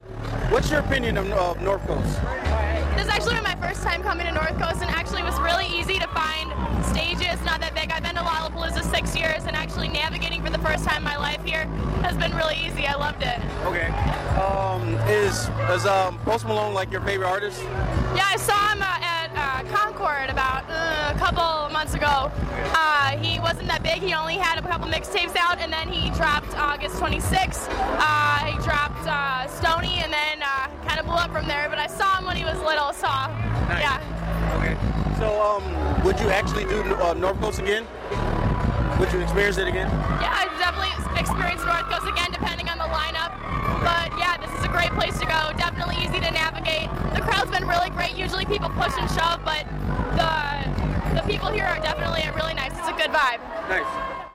This brand new series will feature raw and straight to the point interviews with fans who toughed it out to get the coveted front row guardrail spot for their favorite artist’s sets.